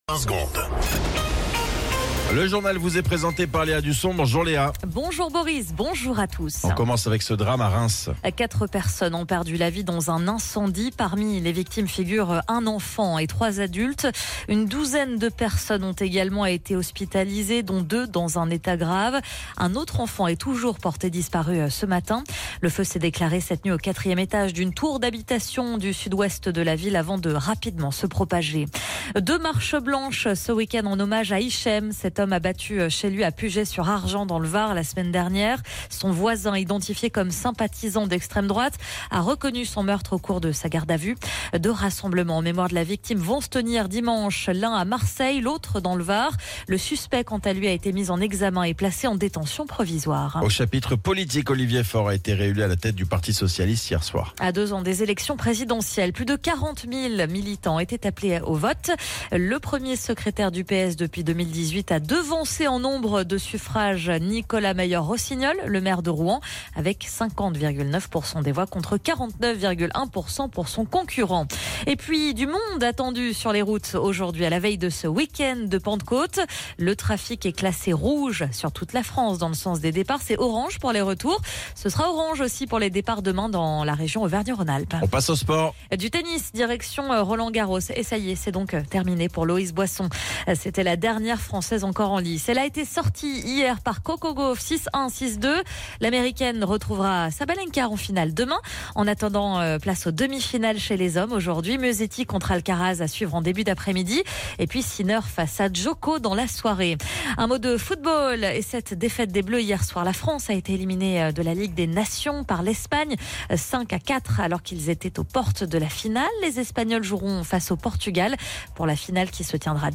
Flash Info National 06 Juin 2025 Du 06/06/2025 à 07h10 .